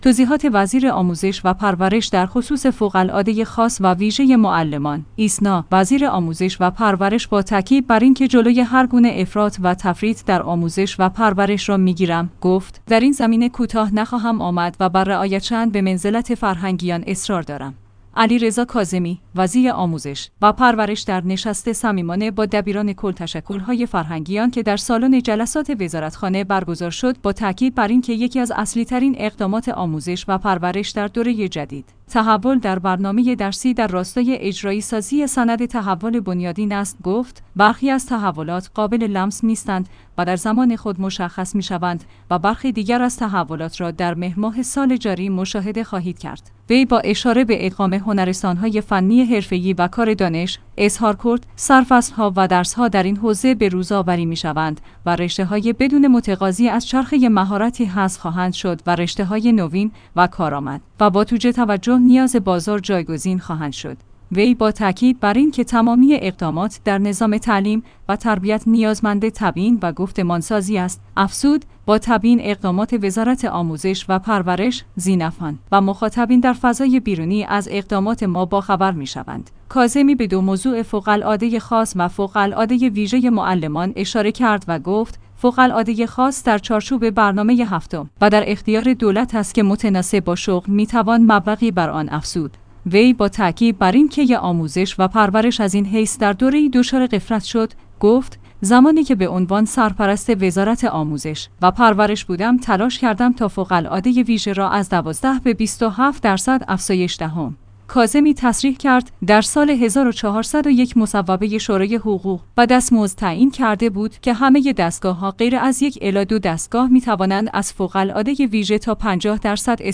توضیحات وزیر آموزش و پرورش درخصوص فوق العاده خاص و ویژه معلمان